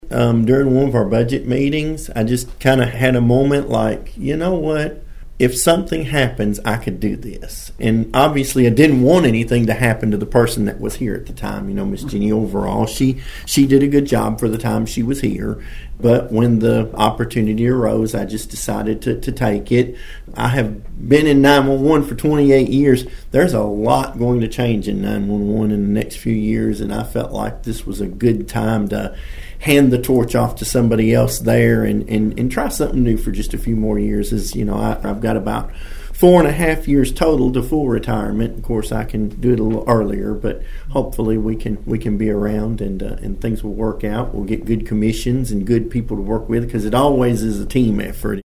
Griggs told Thunderbolt News about his desire to seek the open position.(AUDIO)